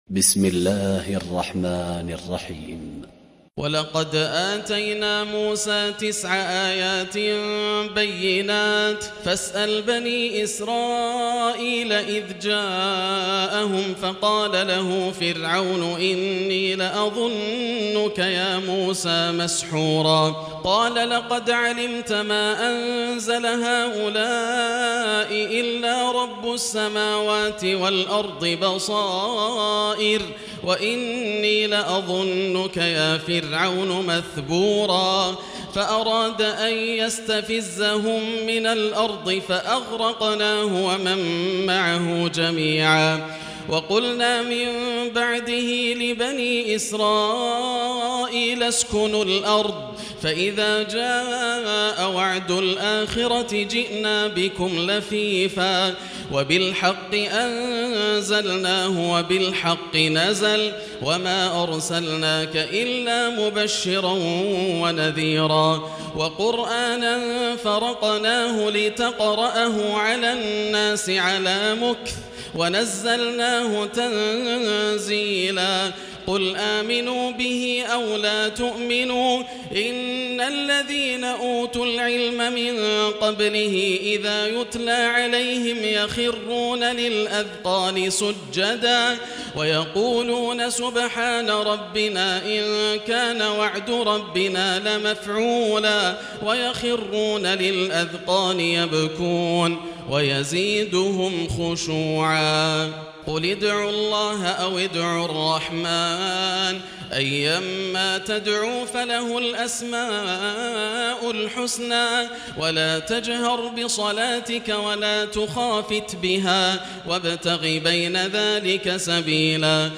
تراويح الليلة الرابعة عشر رمضان 1440هـ من سورتي الإسراء (101-111) والكهف (1-82) Taraweeh 14 st night Ramadan 1440H from Surah Al-Israa and Al-Kahf > تراويح الحرم المكي عام 1440 🕋 > التراويح - تلاوات الحرمين